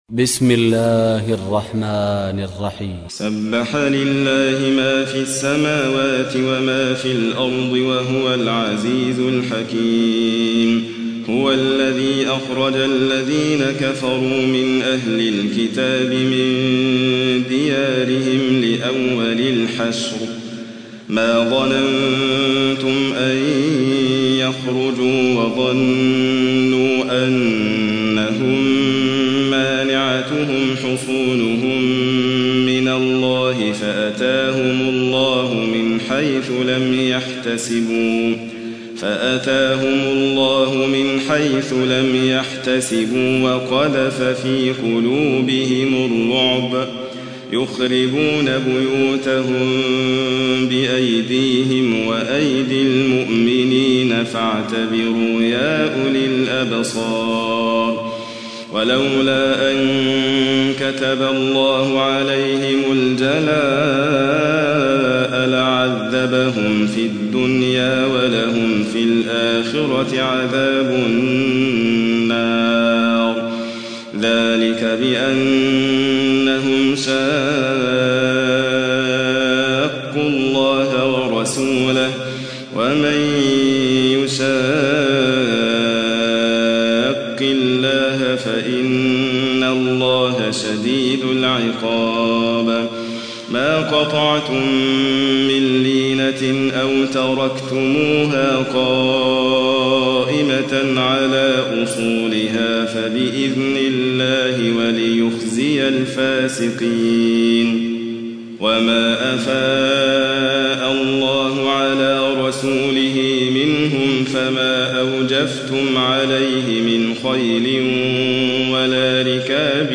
تحميل : 59. سورة الحشر / القارئ حاتم فريد الواعر / القرآن الكريم / موقع يا حسين